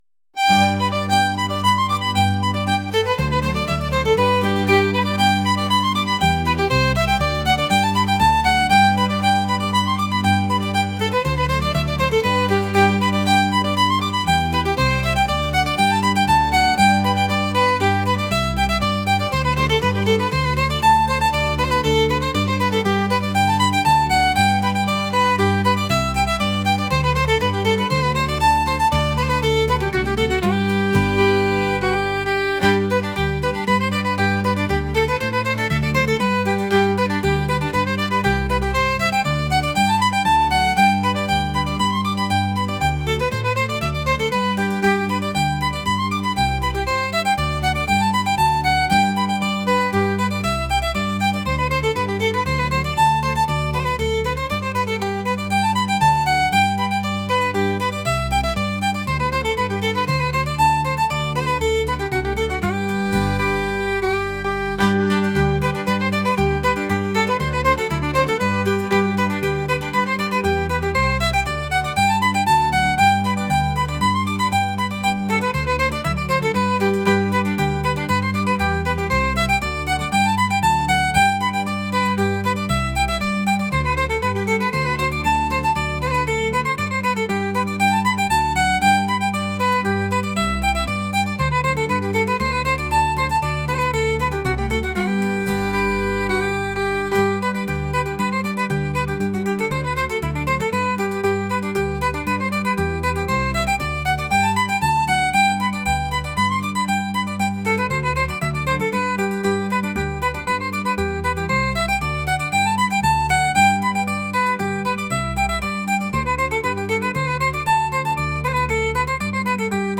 traditional | folk